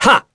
Esker-Vox_Attack2.wav